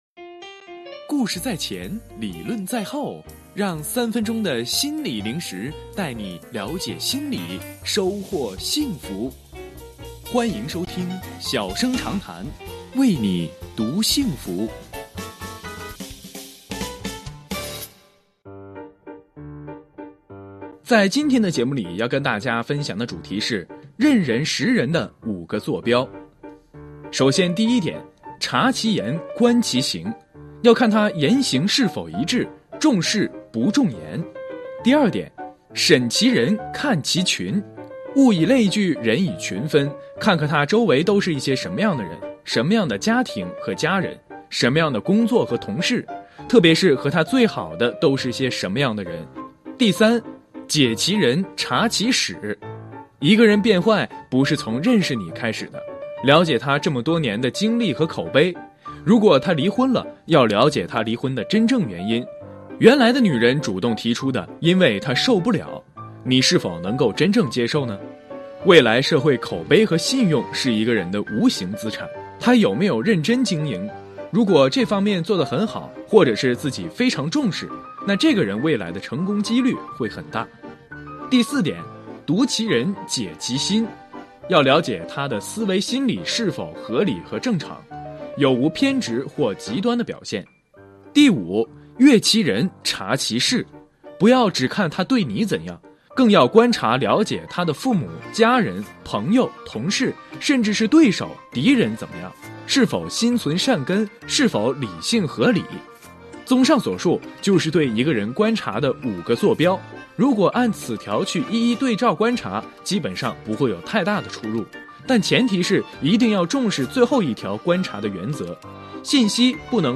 音频来源：吉林广播电视台 新闻综合广播 钟晓工作室